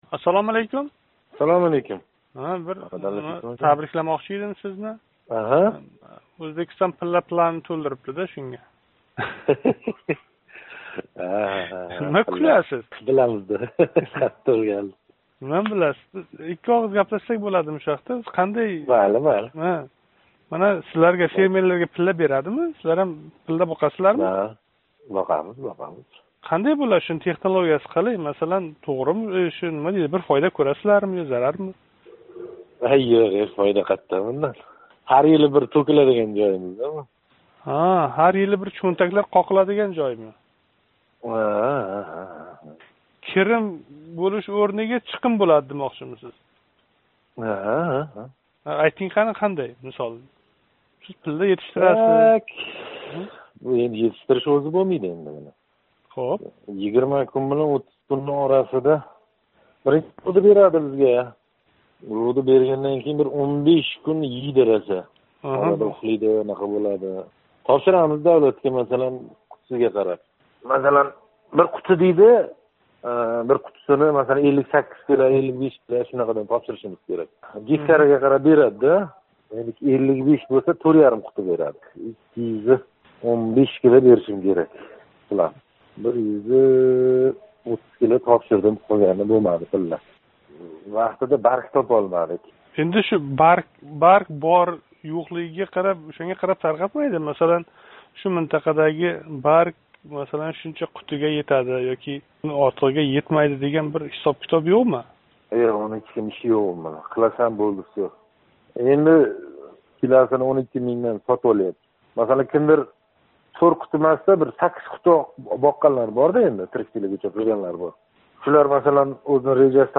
Самарқандлик собиқ хўжалик раиси билан суҳбат: